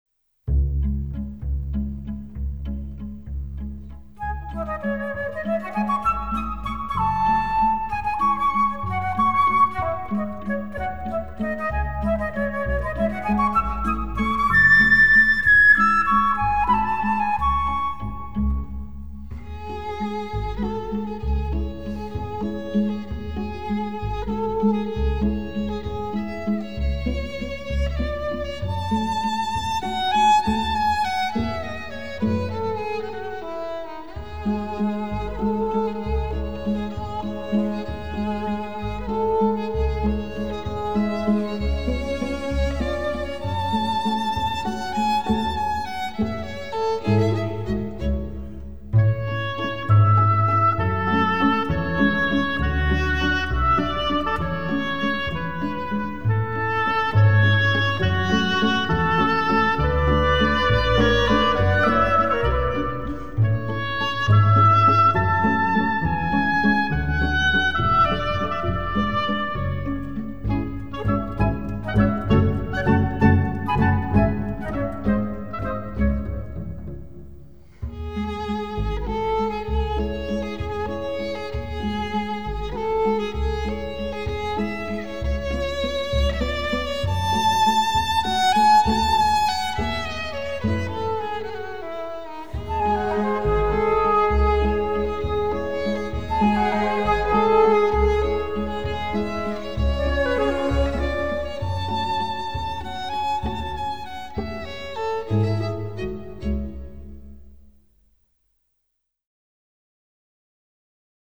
Прислушайтесь к нежной музыке русской души...